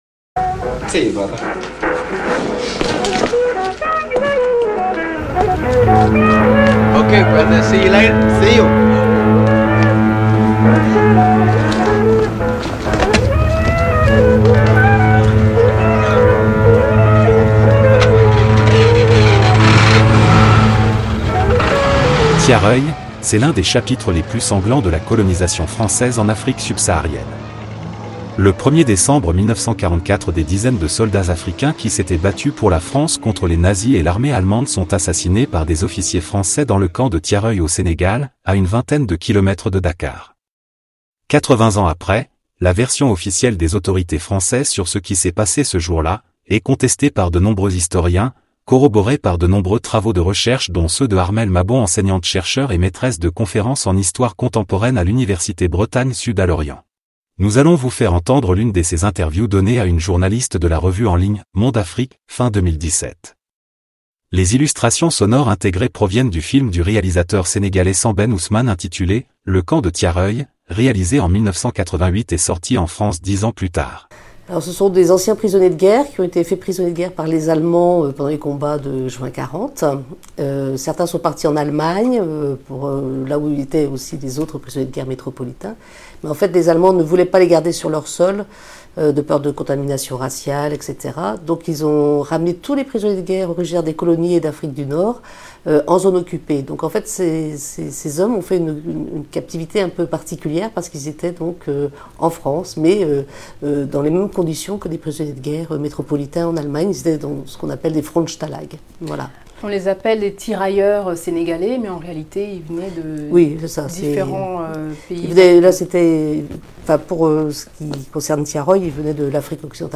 Emissions réalisées à partir du coffret "Mémoires de Tirailleurs" , les anciens combattants d'Afrique noire racontent...